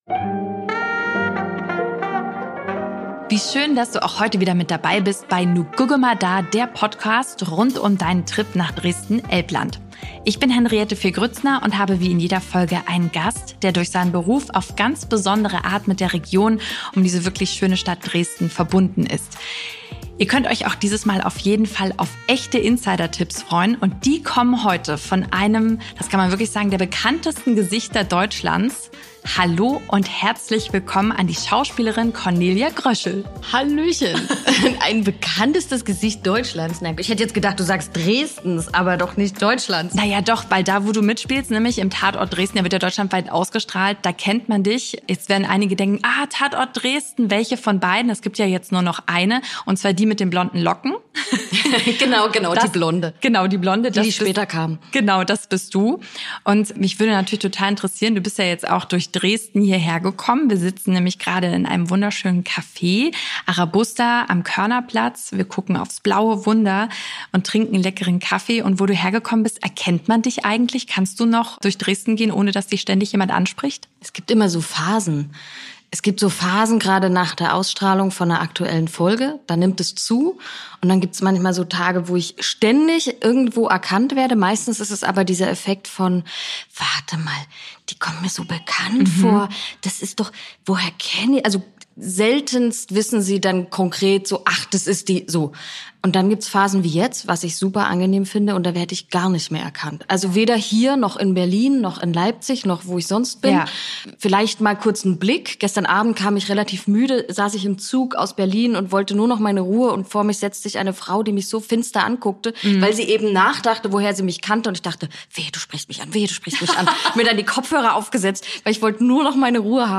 Zwischen inspirierenden Gesprächen und spontanen Momenten entstehen lebendige Einblicke in Dresden Elbland und die besondere Verbindung der Schauspielerin zur Region.